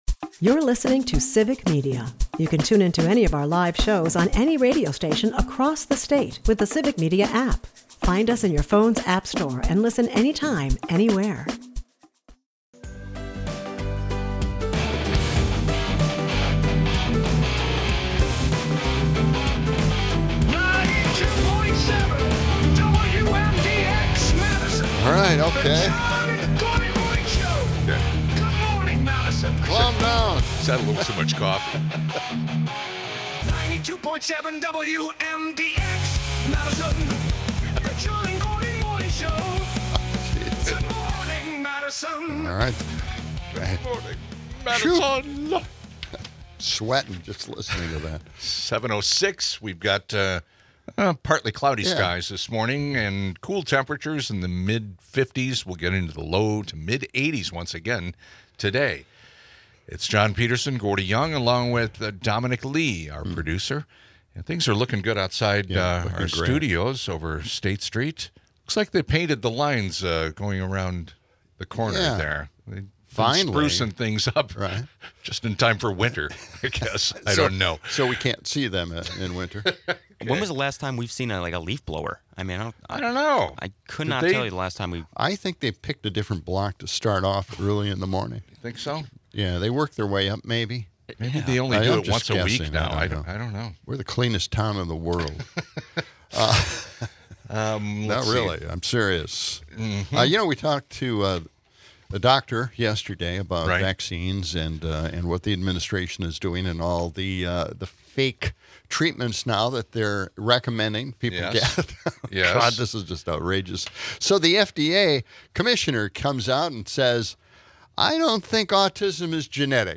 The episode wraps with listener calls on race and religion in America, and a spirited debate featuring Charlie Kirk, exposing deep-seated societal divides.